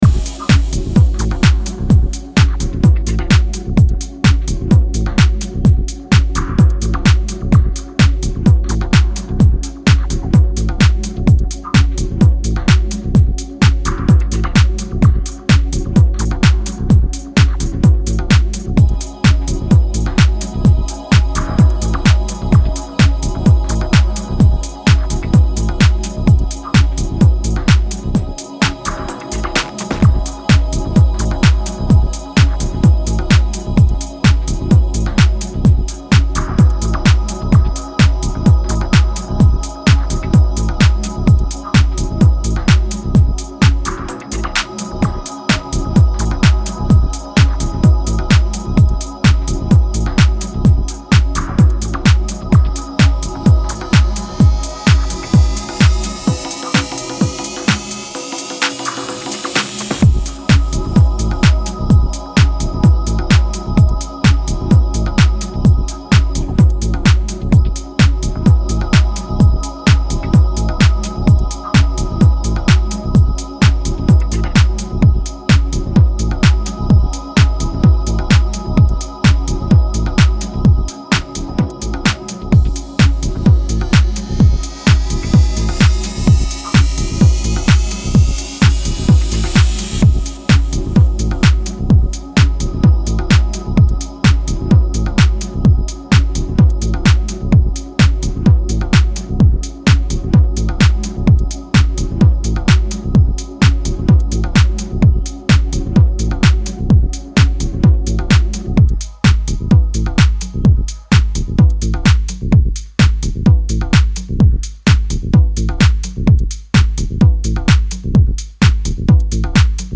いずれもストイックに研ぎ澄まされたグルーヴ